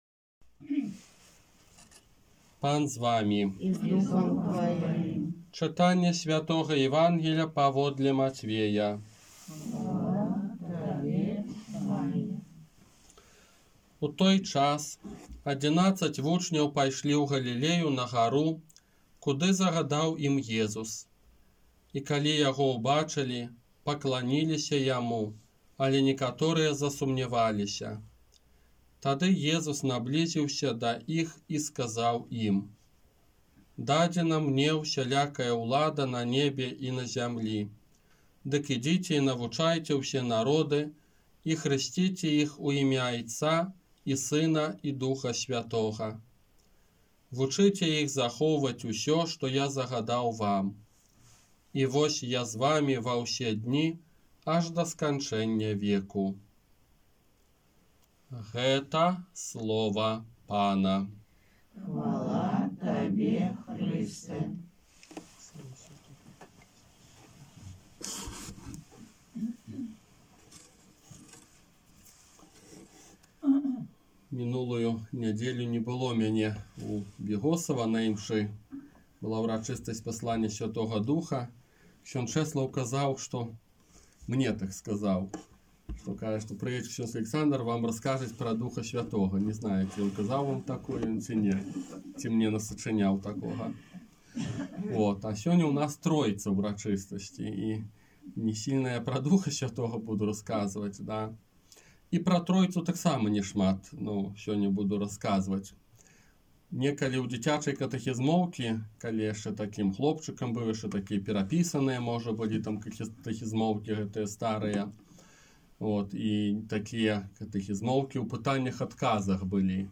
Казанне на ўрачыстасць Найсвяцейшай Тройцы